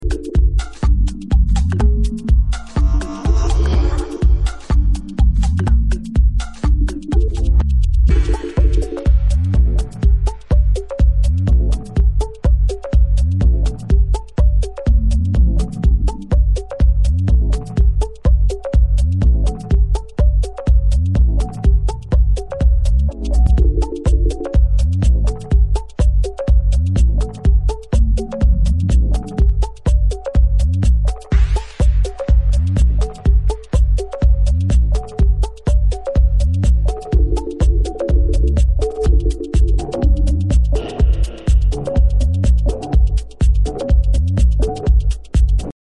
Modern jacking house tracks